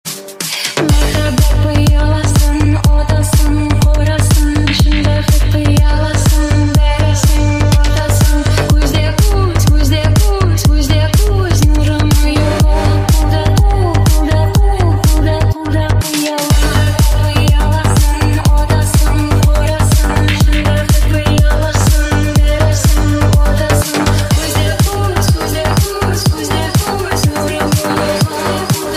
Рингтоны новинки
клубные рингтоны 2024